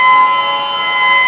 rr3-assets/files/.depot/audio/sfx/forced_induction/supercharger_highload.wav
supercharger_highload.wav